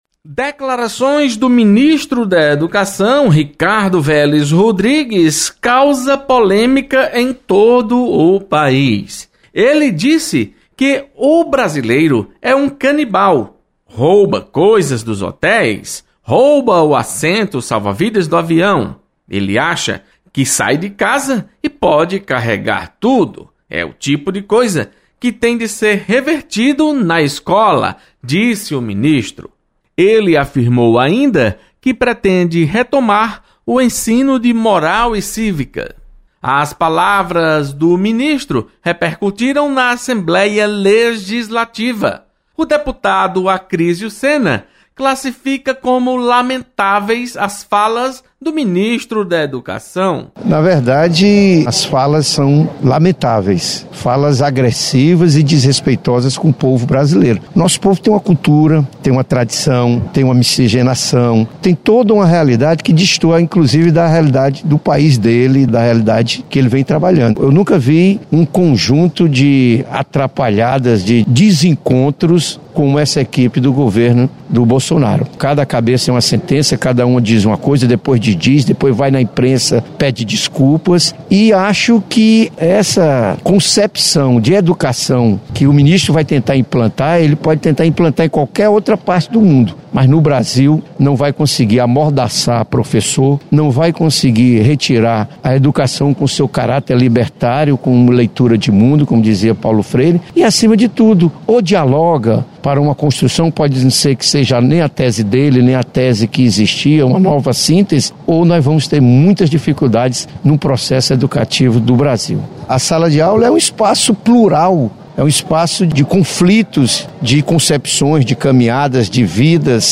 Deputado Acrísio Sena e e deputada Dra. Silvana comentam sobre declarações do ministro da educação.